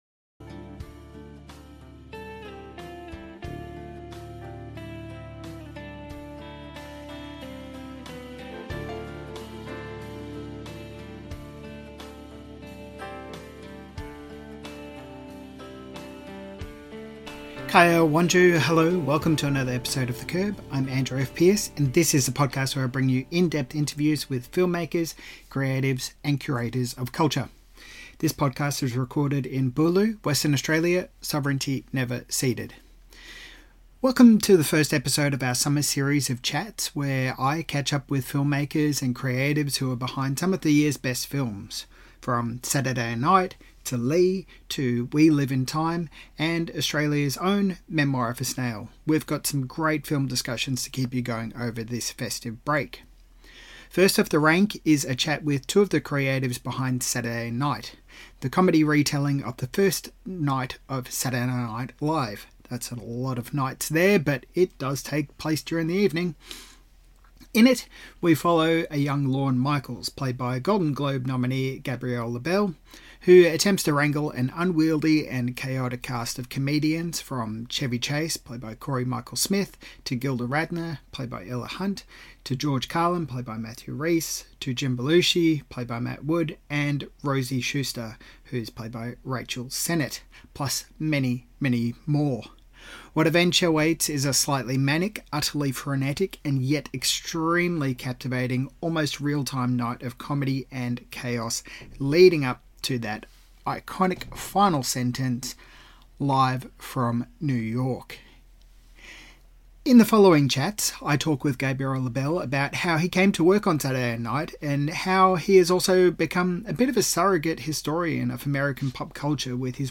In the following conversation, Kate talks about the journey of bringing Lee's story to life, including the importance of engaging in era-specific photography, as well as the need to be able to tell these kinds of stories in todays day and age. This conversation is pulled from two separate discussions, one from a panel discussion, and the second as a one on one interview with Kate. In the first discussion, I was briefly interrupted, which is what you will hear in the following chat.